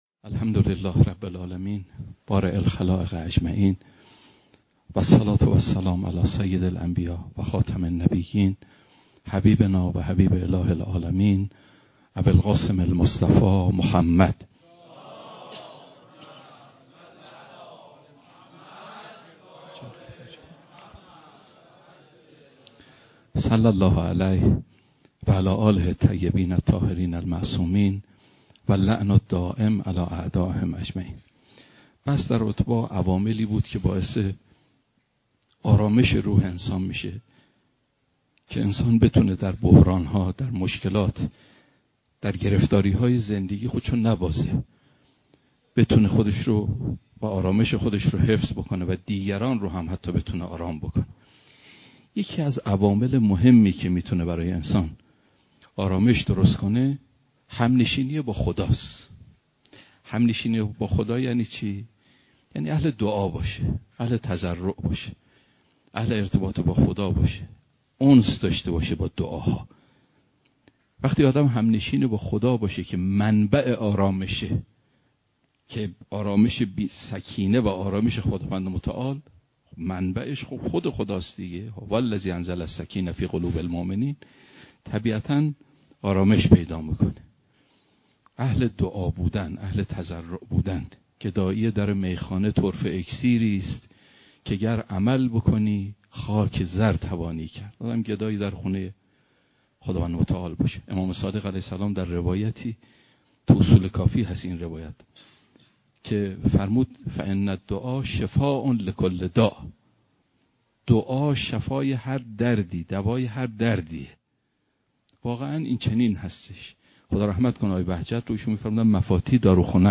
بیانات معرفتی